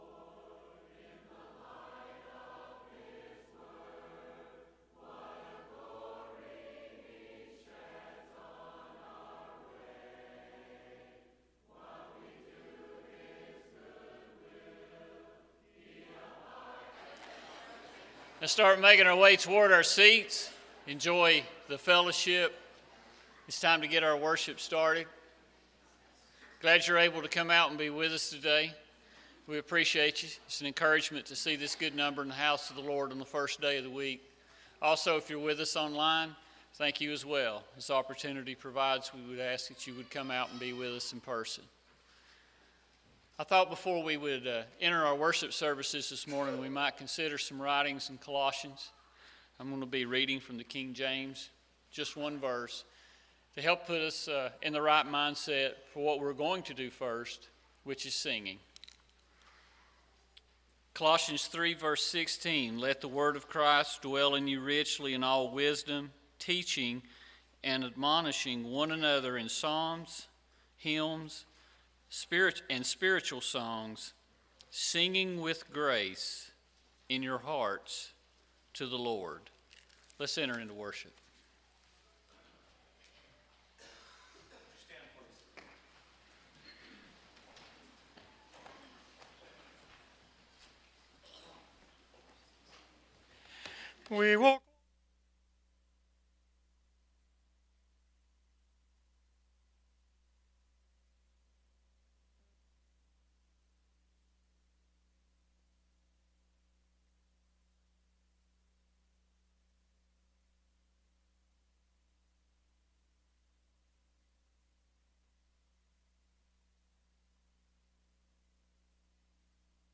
Matthew 7:14, English Standard Version Series: Sunday AM Service